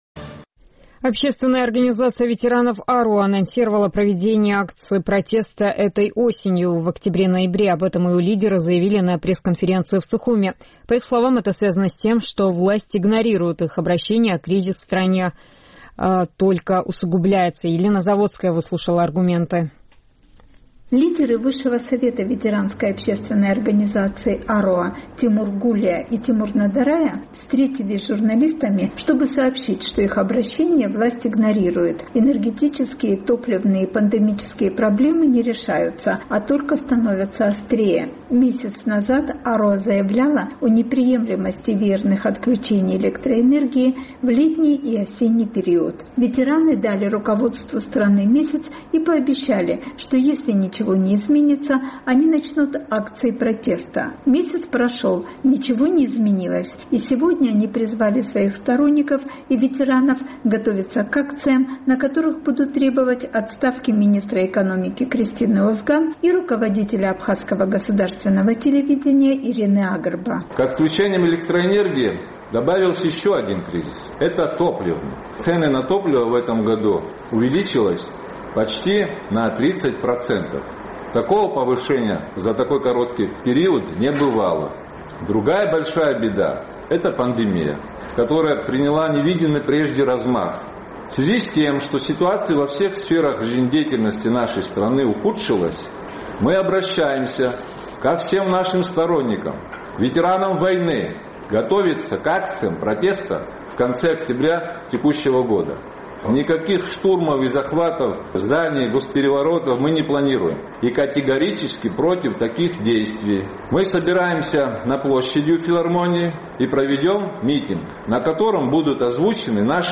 Общественная организация ветеранов «Аруаа» намерена провести акции протеста этой осенью – в октябре-ноябре. Об этом ее лидеры заявили на пресс-конференции в Сухуме.